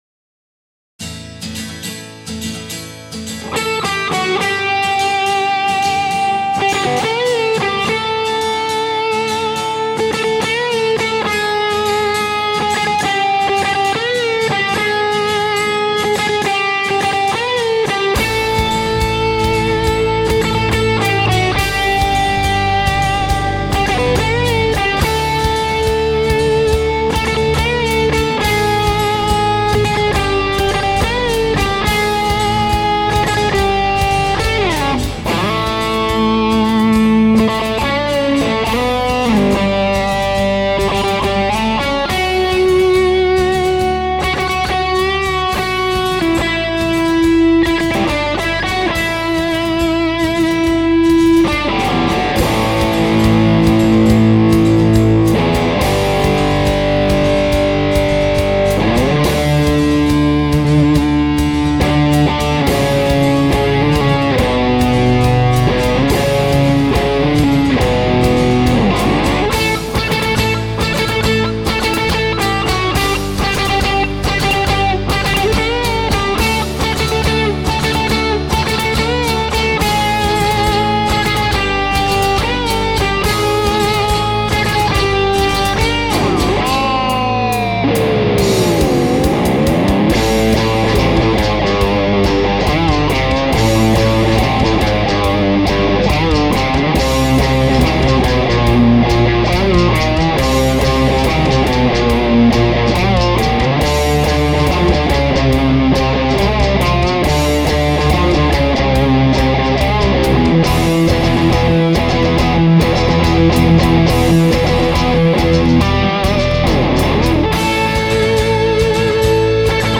Plexi 50 demo tunes